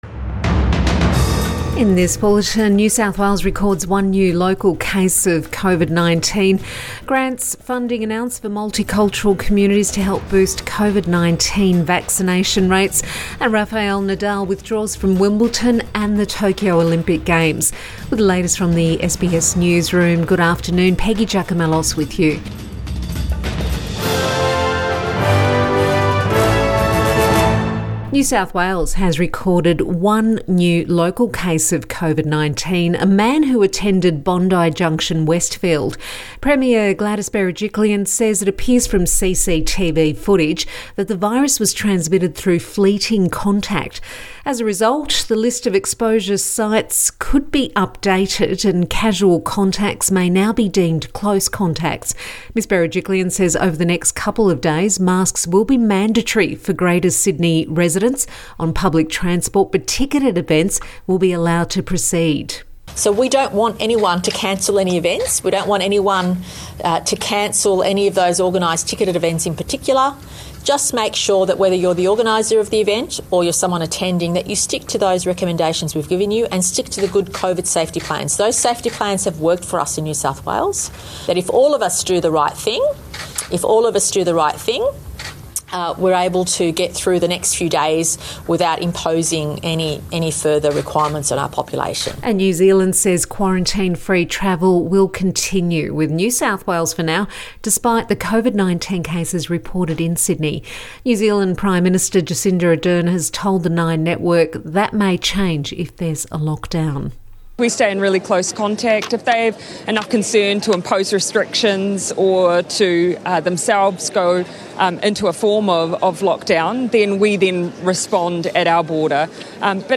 Midday bulletin 18 June 2021